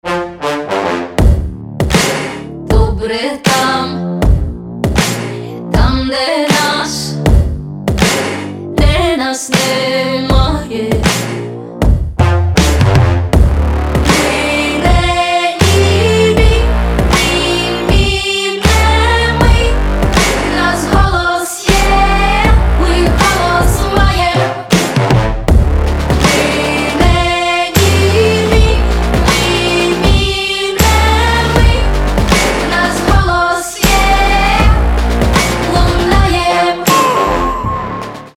• Качество: 320, Stereo
ритмичные
Electronic
Bass
Electropop